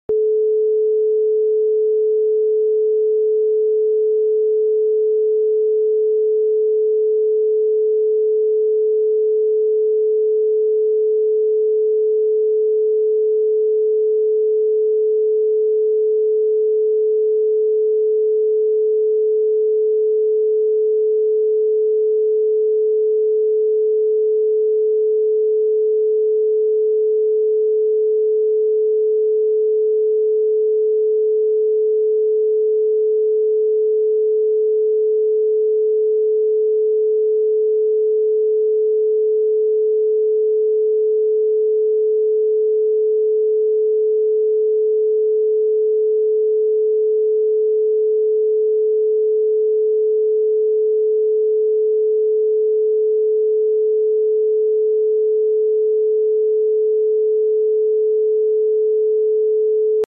432 Hz. solfeggio frequency. Great sound effects free download